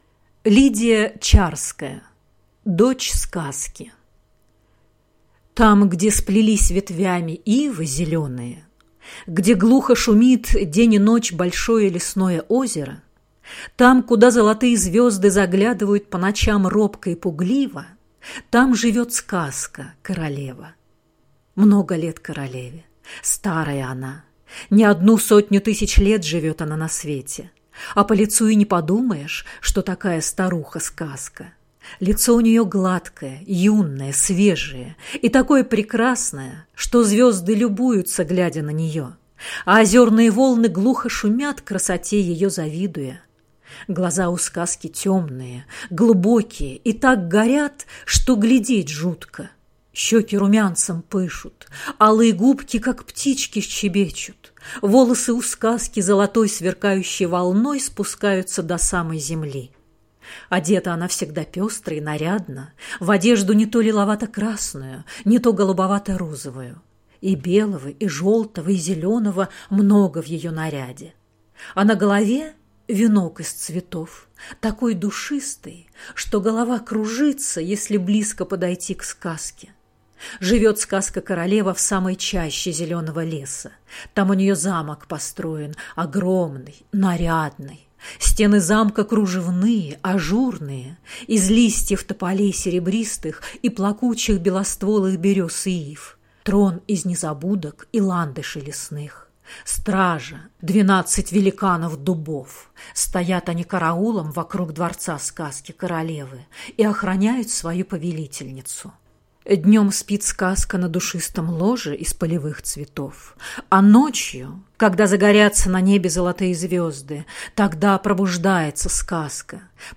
Аудиокнига Дочь Сказки